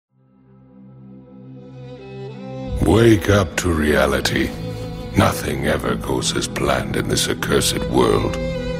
Download Reality Show sound effect for free.